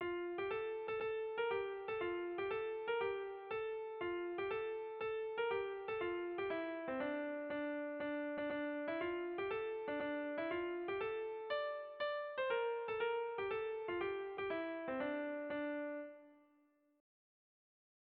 Kontakizunezkoa
Zortziko txikia (hg) / Lau puntuko txikia (ip)
A-A2-B-C